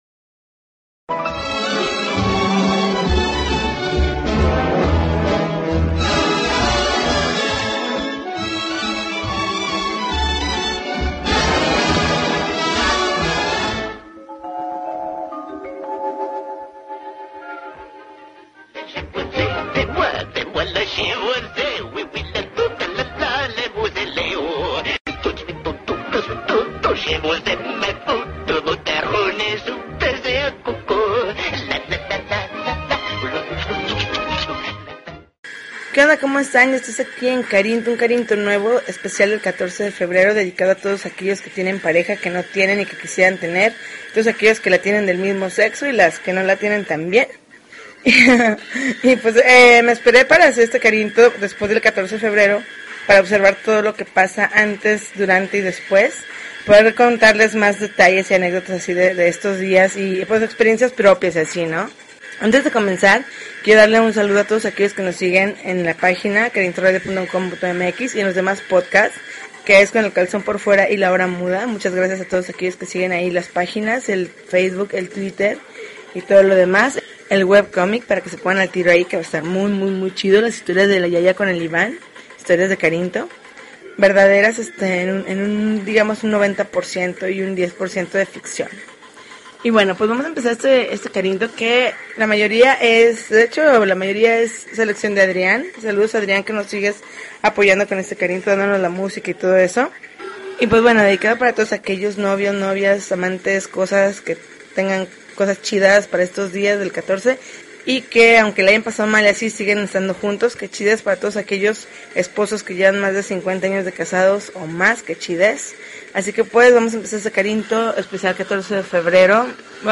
February 19, 2013Podcast, Punk Rock Alternativo